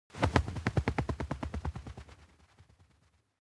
bird_fly_away.ogg